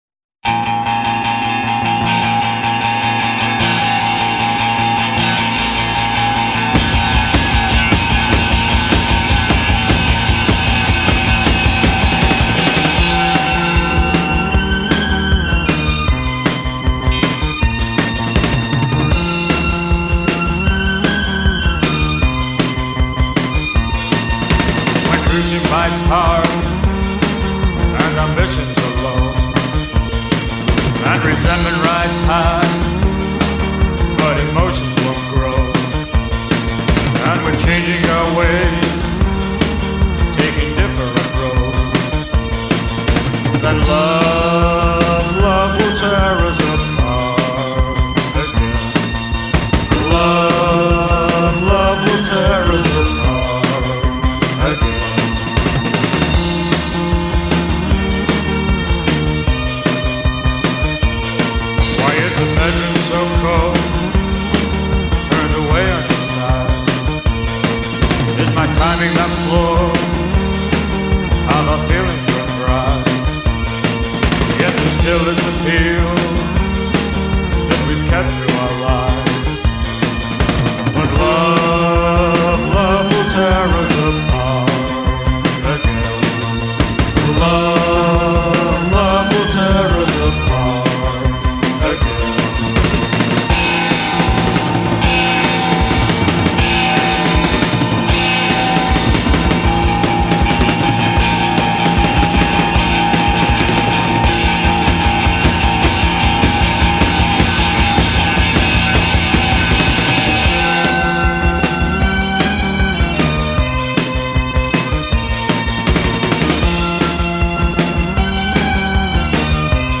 icons of the Post-Punk Movement.
towards a deeper more introspective sound.